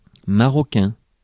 Ääntäminen
Synonyymit chérifien Ääntäminen France: IPA: [ma.ʁɔ.kɛ̃] Haettu sana löytyi näillä lähdekielillä: ranska Käännös Adjektiivit 1.